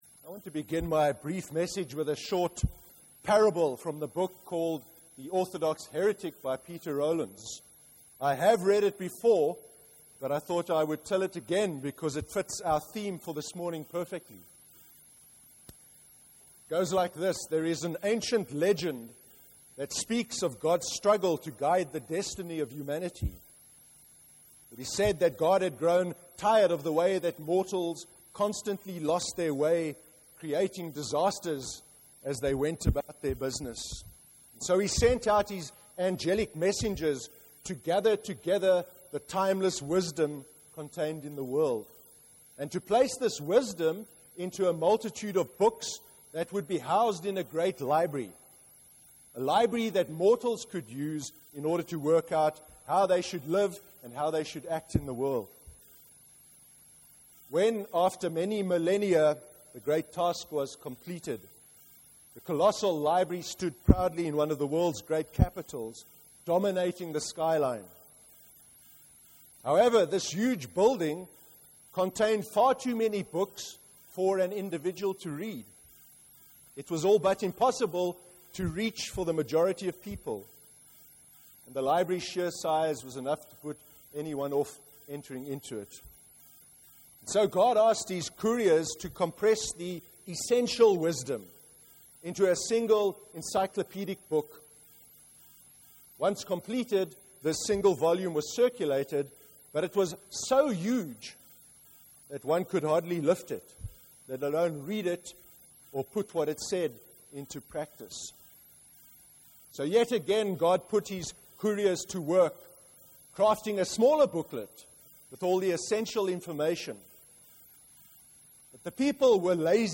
02/02/2014 sermon. Love matters most – NEWHAVEN CHURCH
A recording of the service’s sermon is available to play below, or by right clicking on this link to download the sermon to your computer.